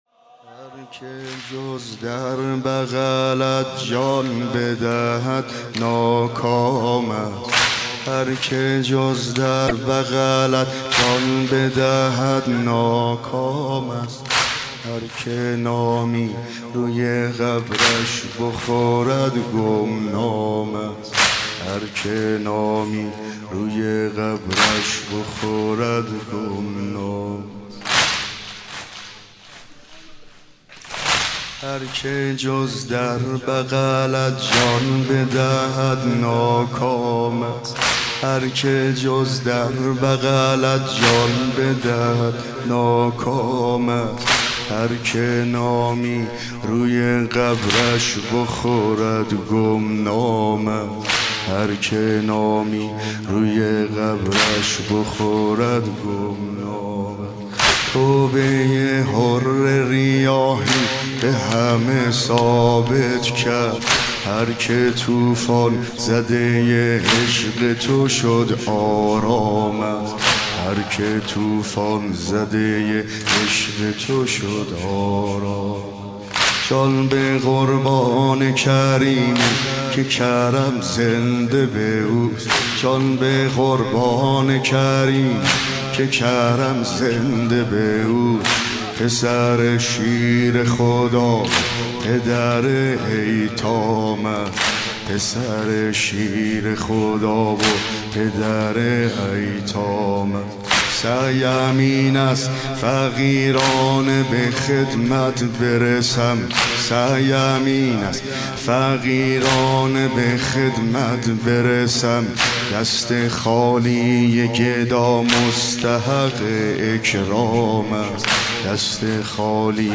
مداحی شب چهارم محرم با نوای عبدالرضا هلالی را بشنوید.